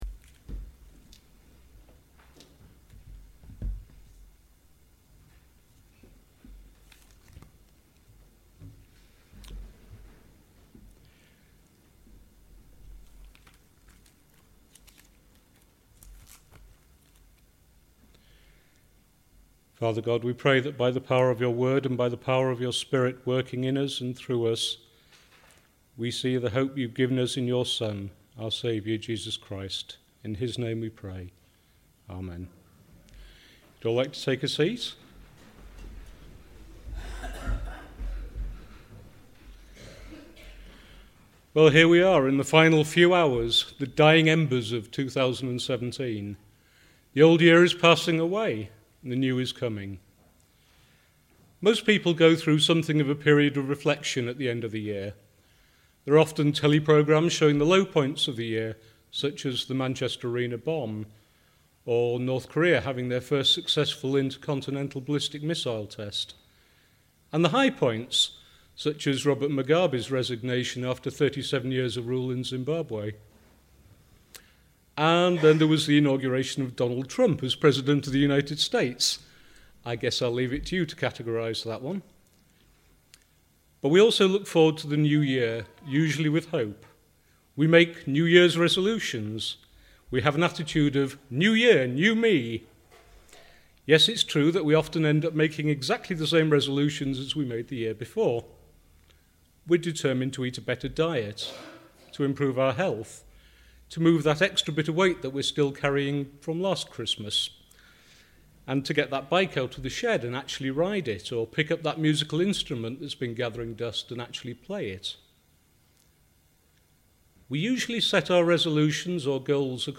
New Year’s Eve day service: sermon